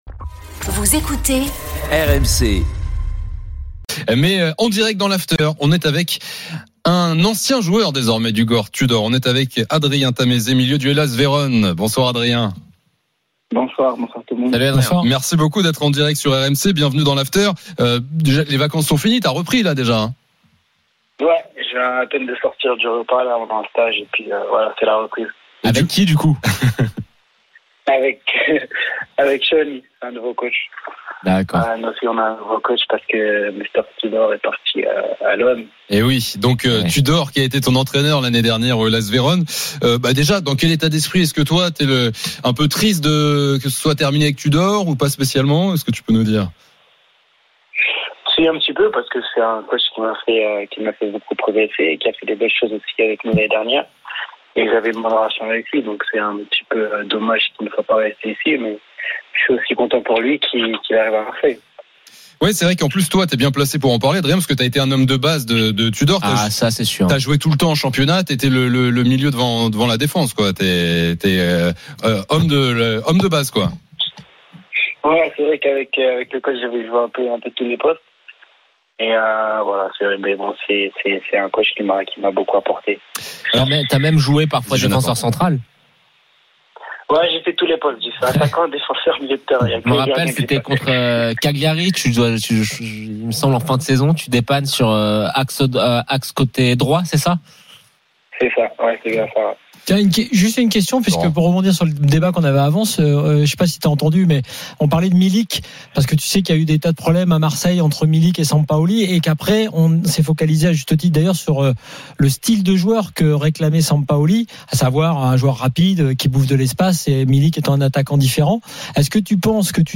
Le Top de l'After Foot : Adrien Tameze, ancien joueur d'Igor Tudor à l'Hellas Verone, invité de l'After Foot – 03/07
Chaque jour, écoutez le Best-of de l'Afterfoot, sur RMC la radio du Sport !